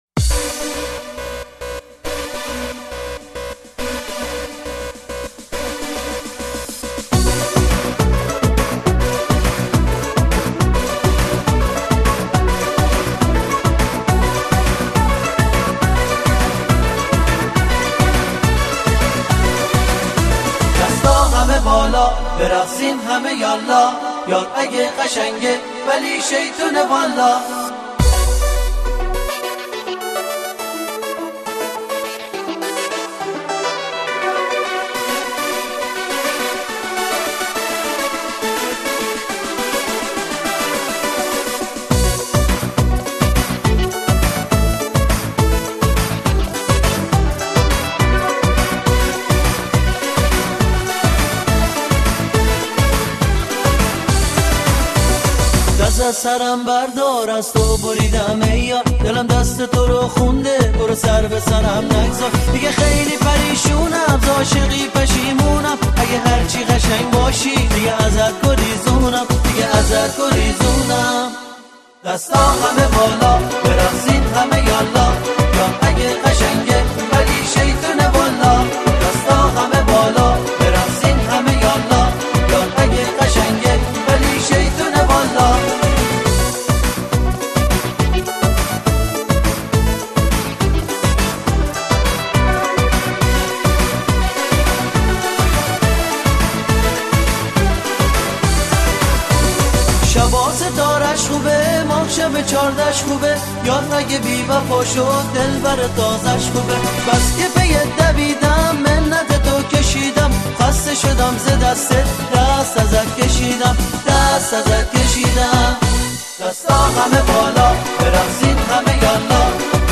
شاد و پرانرژی